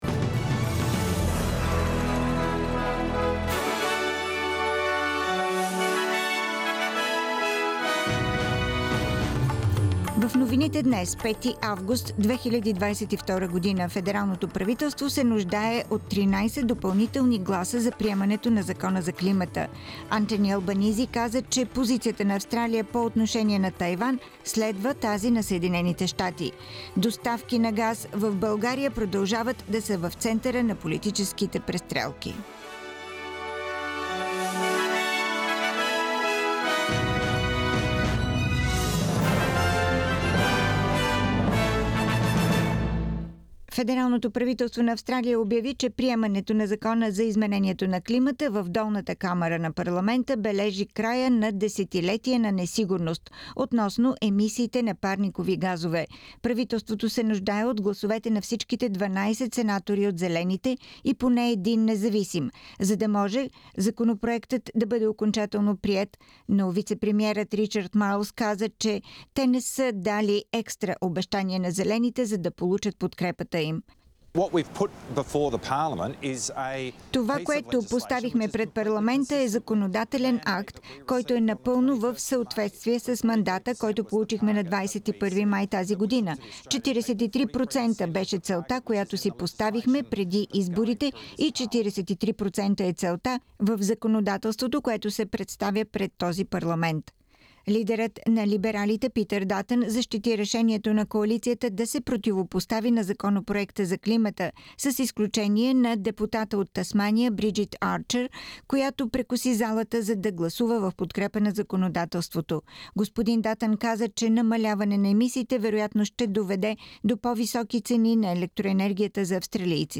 Радио SBS новини на български език - 5-ти август 2022 05:13 Седмичен преглед на новините.